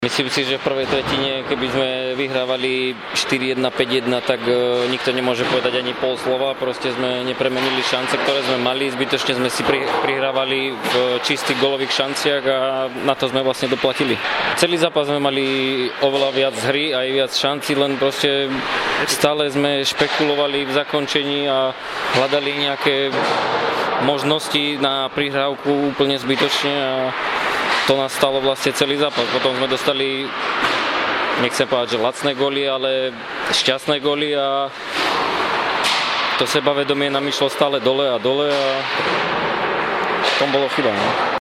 Zhodnotenie zápasu: